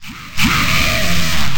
PixelPerfectionCE/assets/minecraft/sounds/mob/wither/shoot.ogg at mc116
shoot.ogg